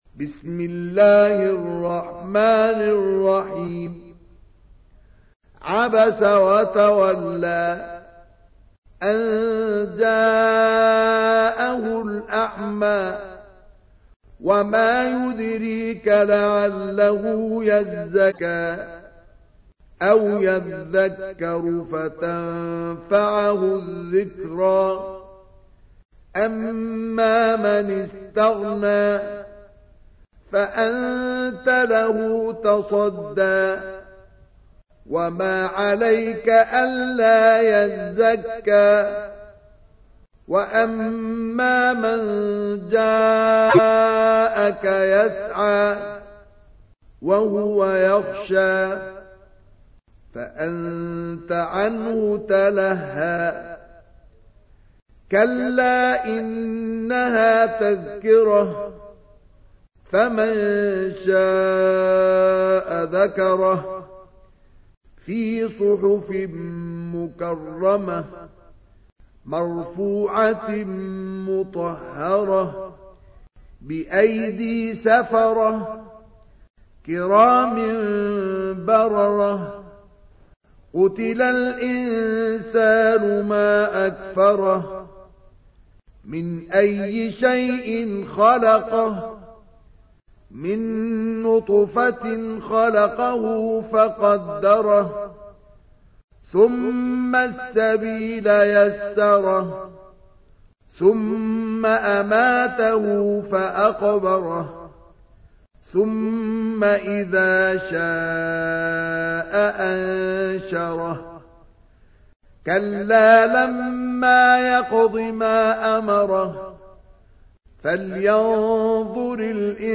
تحميل : 80. سورة عبس / القارئ مصطفى اسماعيل / القرآن الكريم / موقع يا حسين